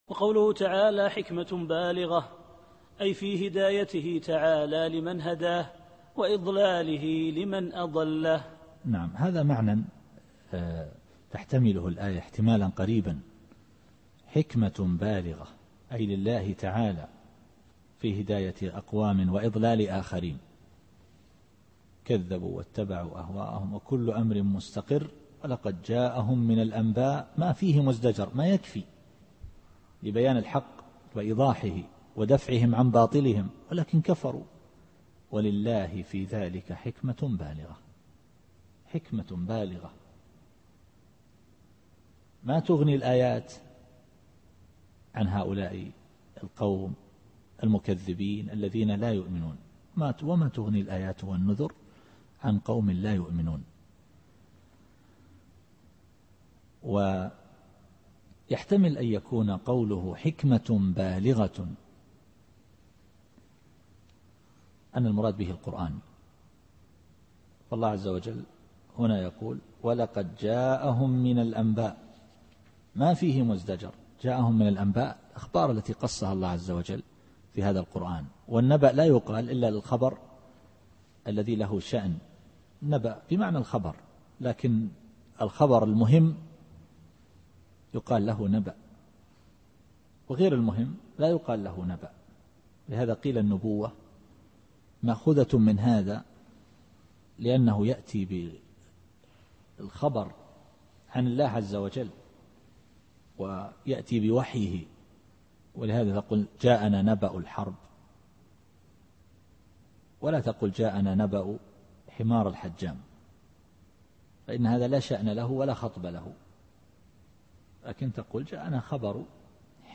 التفسير الصوتي [القمر / 5]